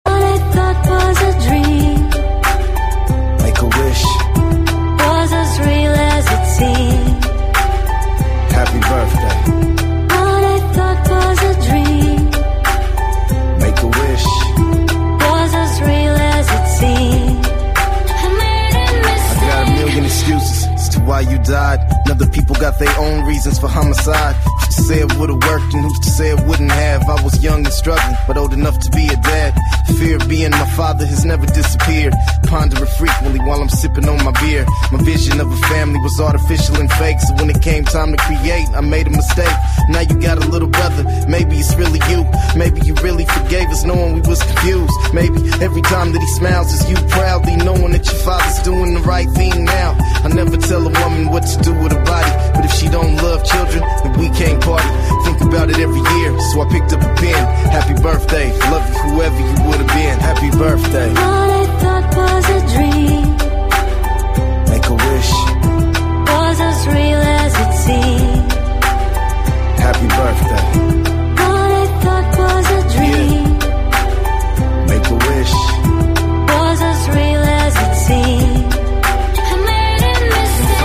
рэп припев